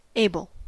Ääntäminen
US : IPA : [ˈeɪ.bəl] US : IPA : /ˈeɪ.bl̩/